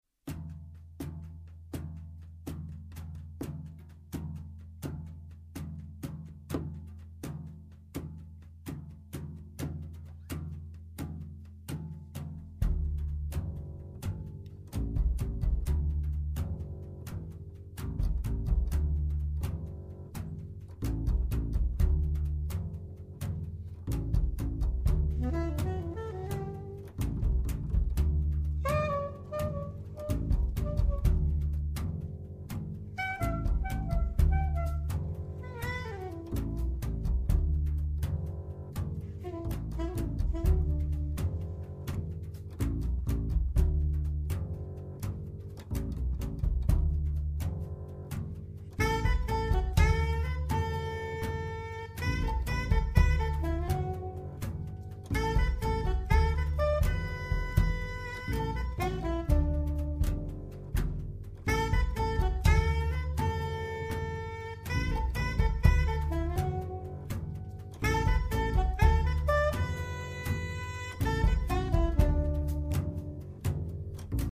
sax tenore e soprano
pianoforte
contrabbasso
batteria